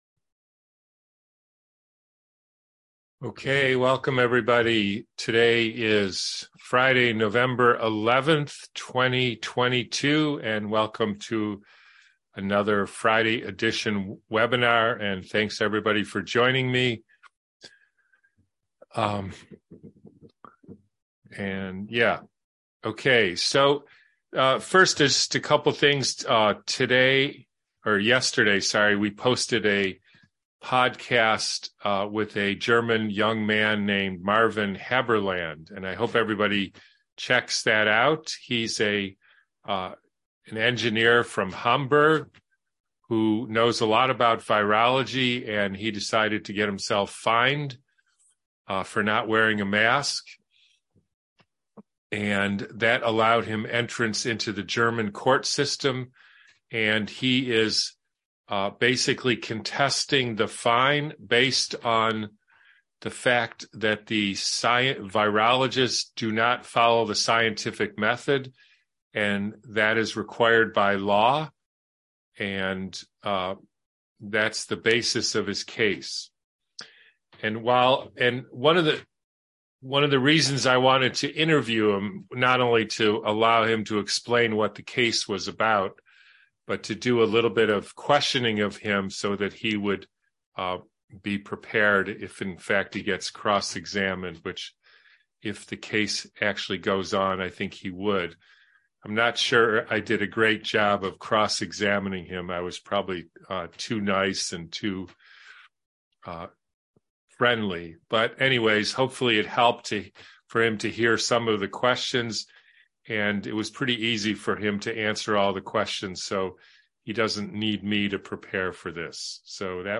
Webinar